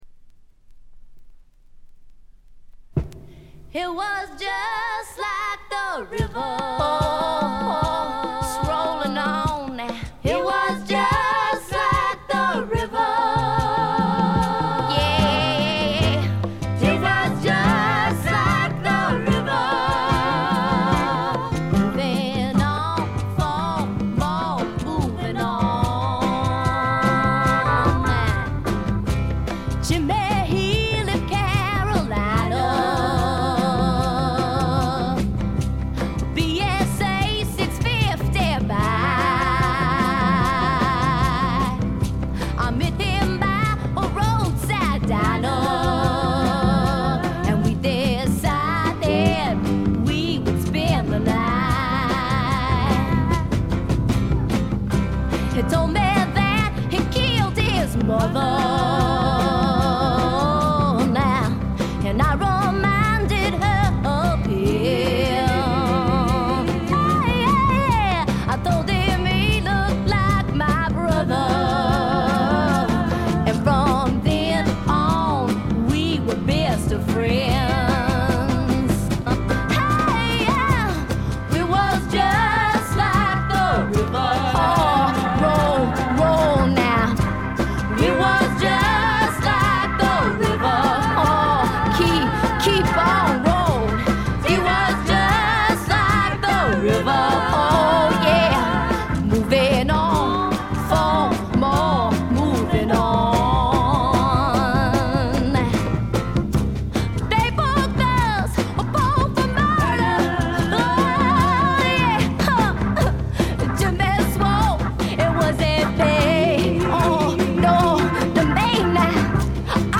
ホーム > レコード：米国 女性SSW / フォーク
ごくわずかなノイズ感のみ。
試聴曲は現品からの取り込み音源です。